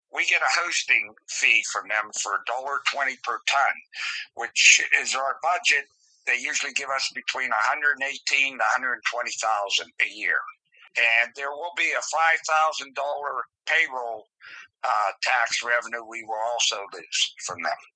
Center Township Supervisor Jim Gatskie said the township will see a negative financial hit from the closure.